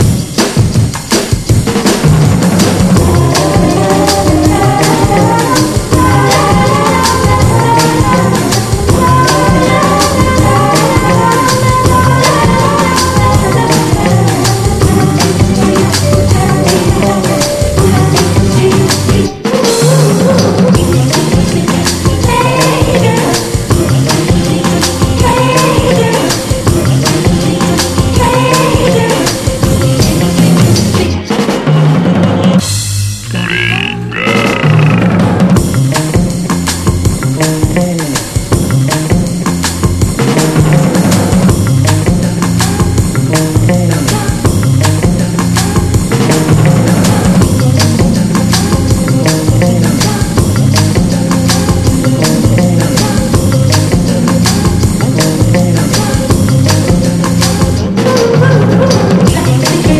JAPANESE PUNK
BREAKBEATS
BREAKCORE
デジタル・ハードコア！